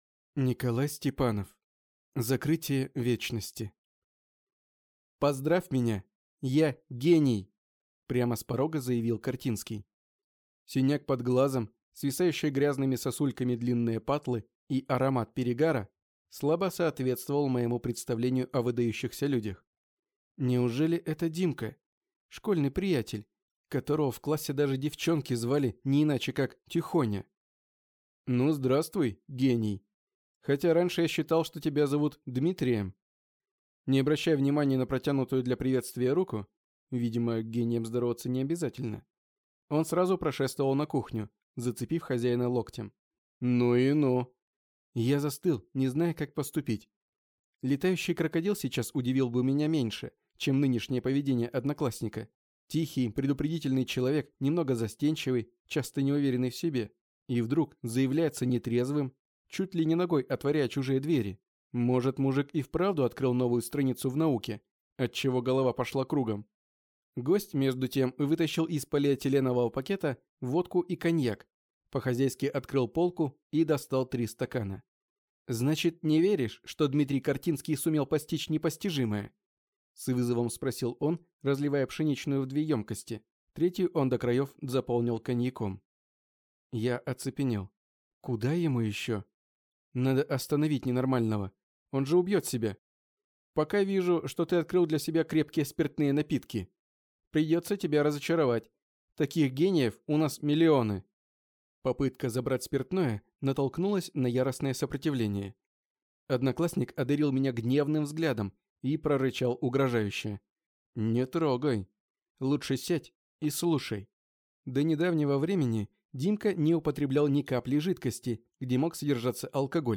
Аудиокнига Закрытие вечности | Библиотека аудиокниг